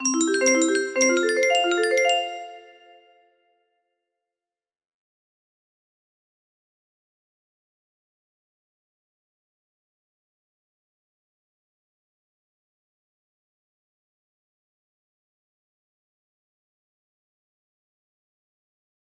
Taper Arp 1 music box melody